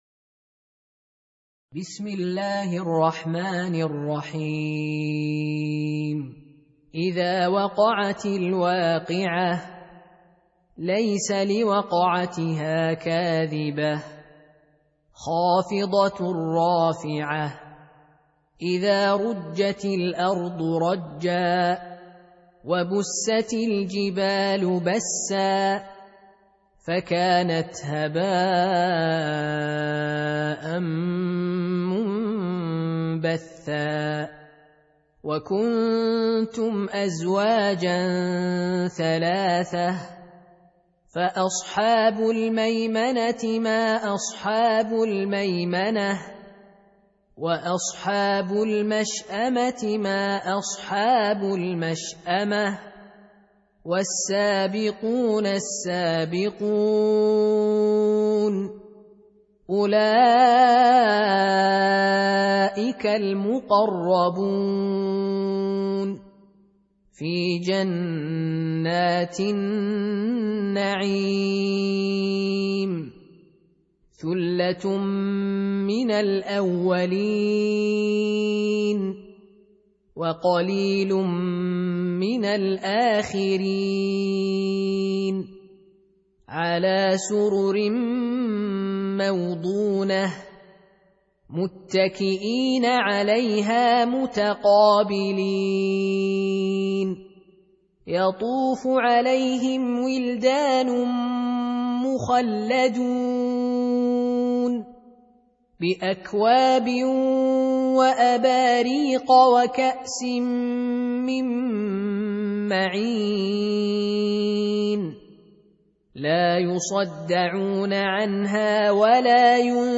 Surah Repeating تكرار السورة Download Surah حمّل السورة Reciting Murattalah Audio for 56. Surah Al-W�qi'ah سورة الواقعة N.B *Surah Includes Al-Basmalah Reciters Sequents تتابع التلاوات Reciters Repeats تكرار التلاوات